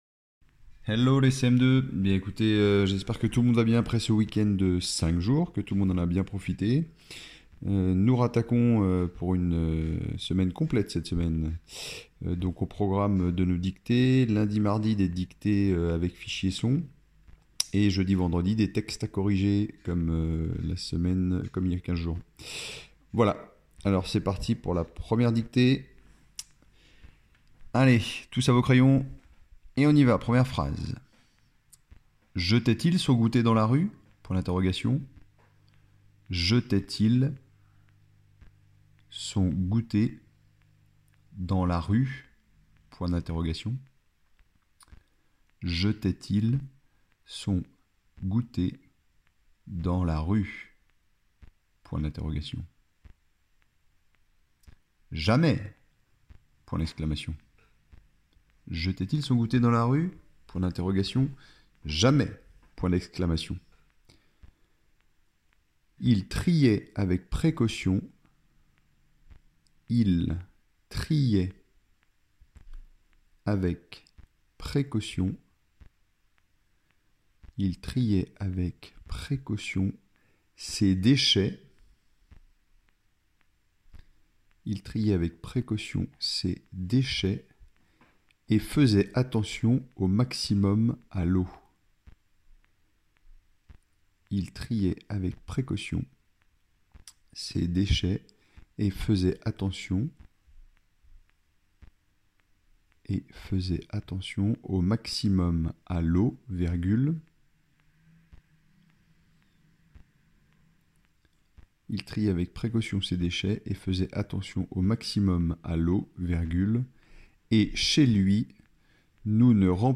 -Dictée :